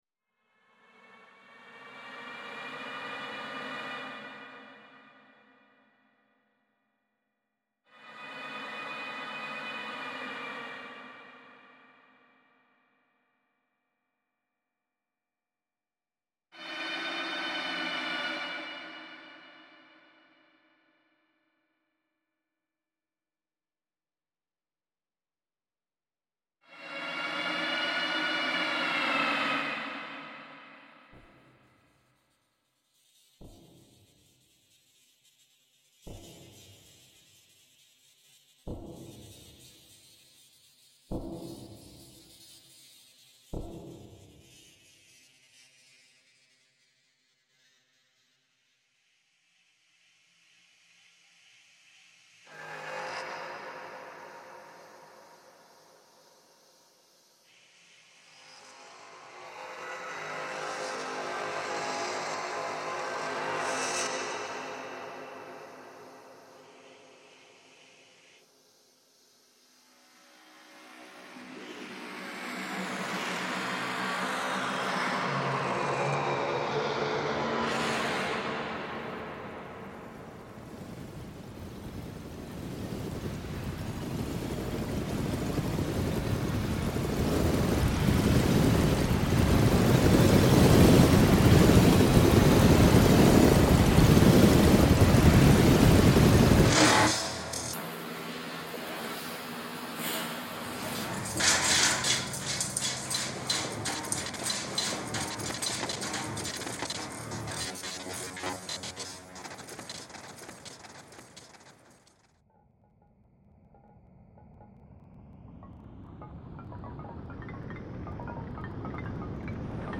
The piece draws from a field recording captured in July 2020 in New York City.
Williamsburg soundscape reimagined